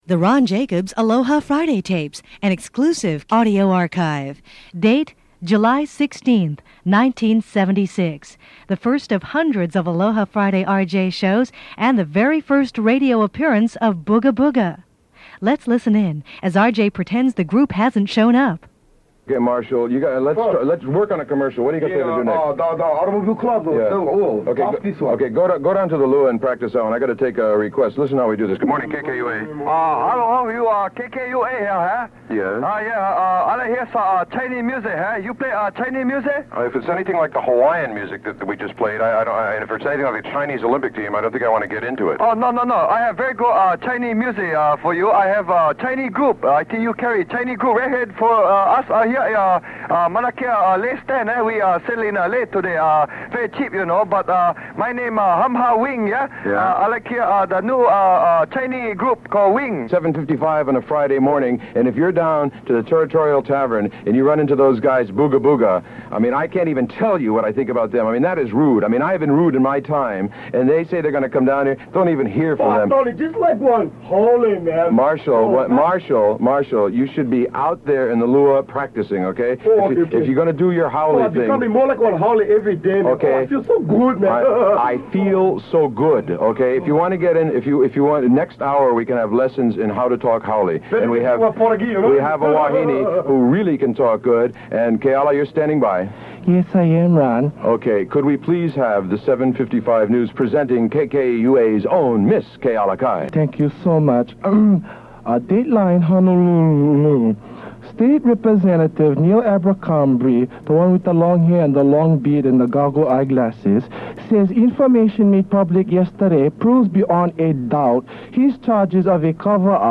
This was the first of 150 plus "Aloha Friday" interview.